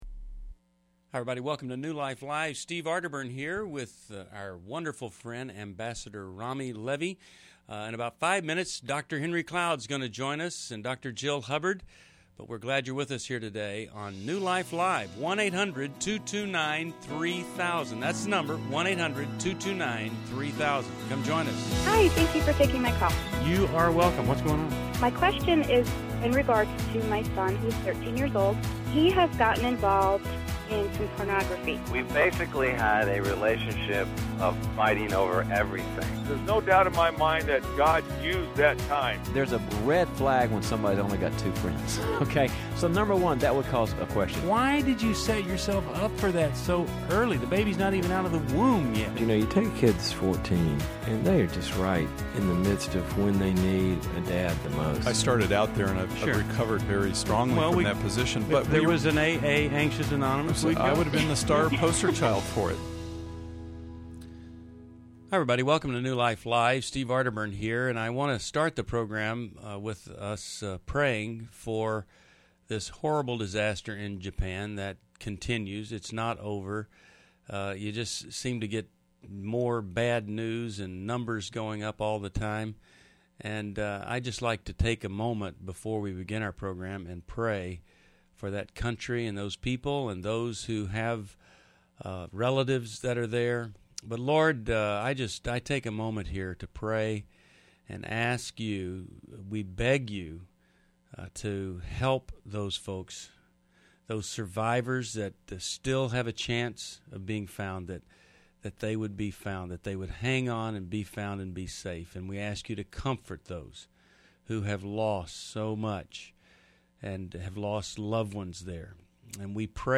Caller Questions: 1. Should I marry a Jehovah’s Witness? 2.